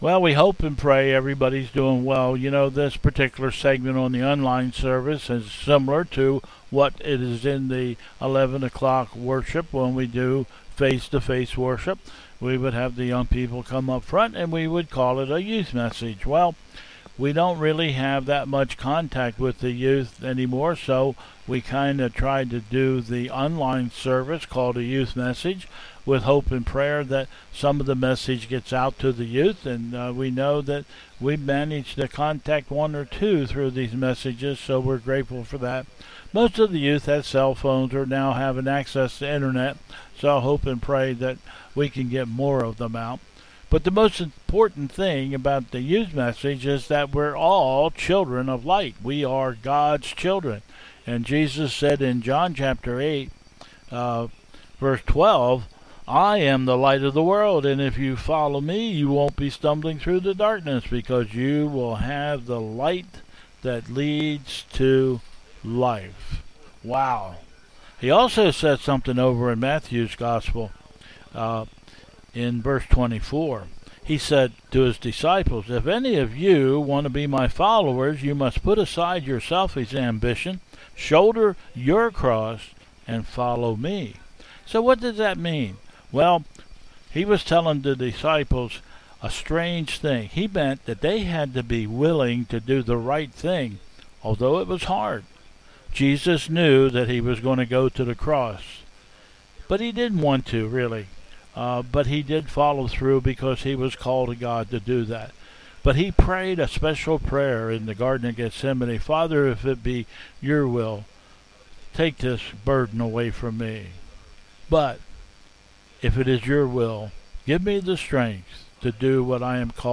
Bethel 08/30/20 Service
Processional